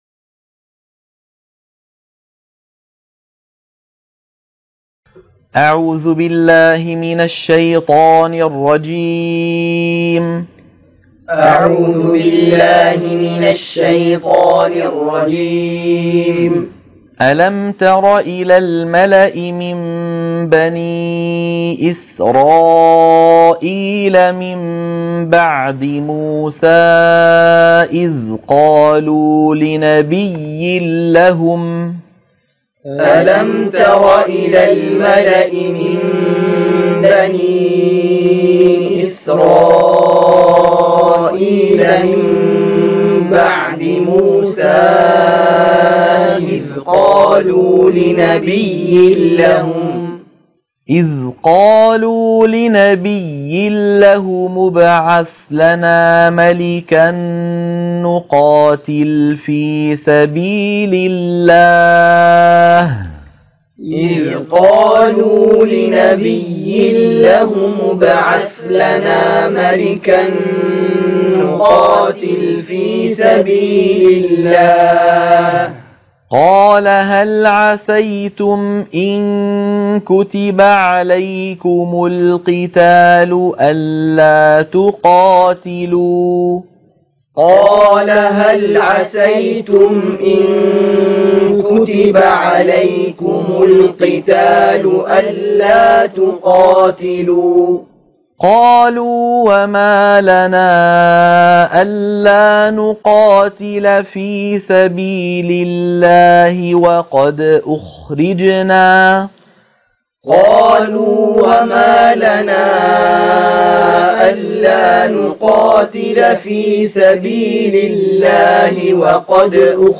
تلقين سورة البقرة - الصفحة 40 التلاوة المنهجية - الشيخ أيمن سويد